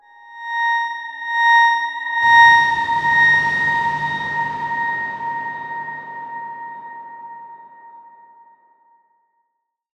X_Darkswarm-A#5-pp.wav